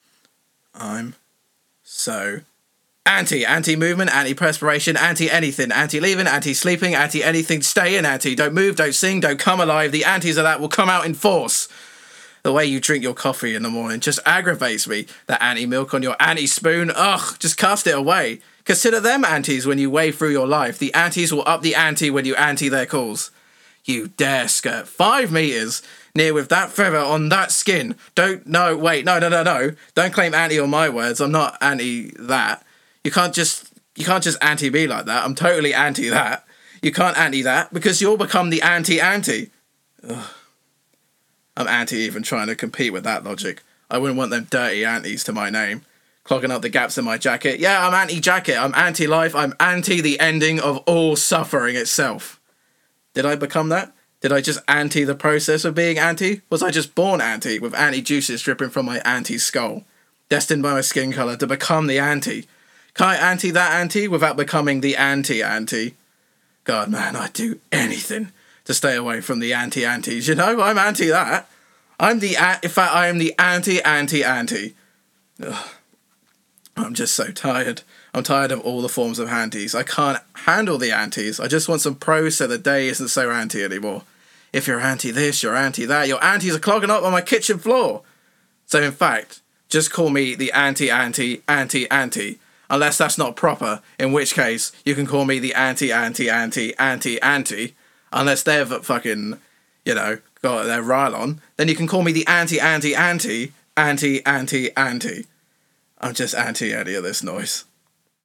Tag: poetry reading